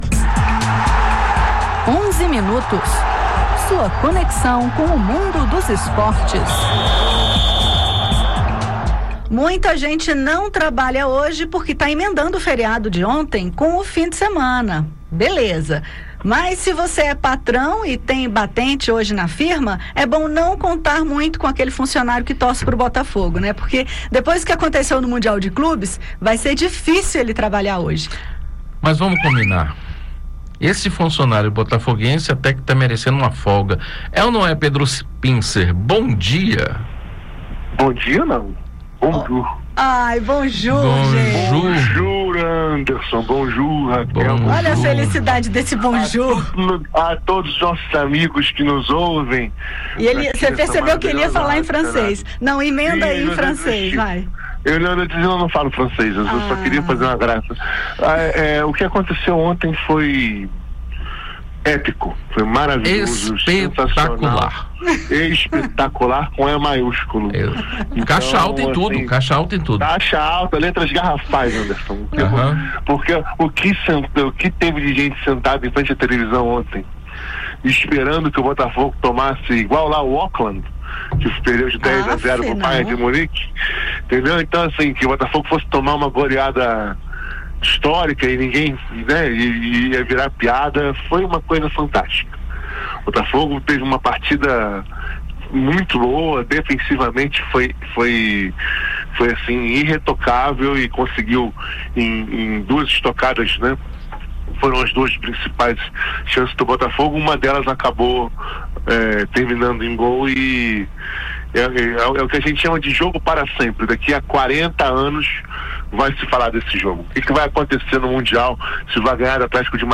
O quadro "Onze Minutos" comenta o desempenho dos times brasileiros no campeonato. A senadora Leila Barros (PDT-DF) declarou torcida às quatro equipes participantes do Mundial: Botafogo, Palmeiras, Flamengo e Fluminense. Ouça também os comentários sobre a Série B do futebol brasileiro, a Liga das Nações de Vôlei, as conquistas de Hugo Calderano e o judô brasileiro no Mundial em Budapeste.